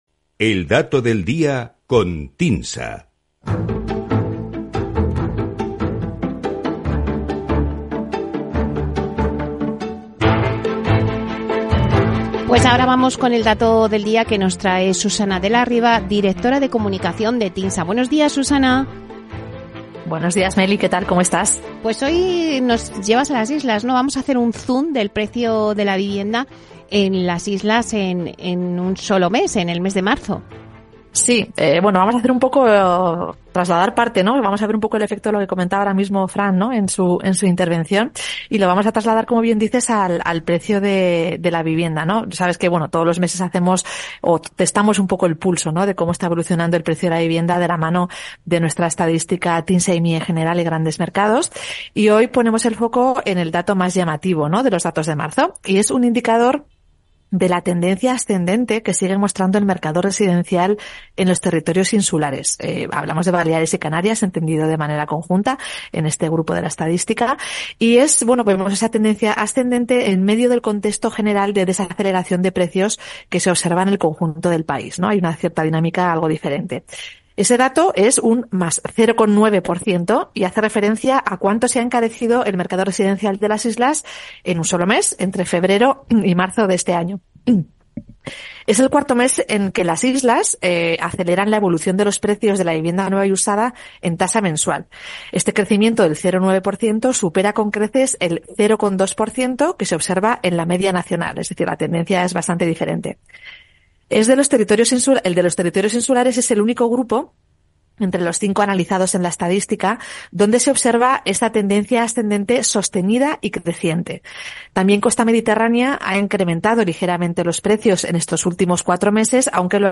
También lo explicamos en el programa Inversión Inmobiliaria de Capital Radio, en la sección semanal ‘El Dato del Día con Tinsa’.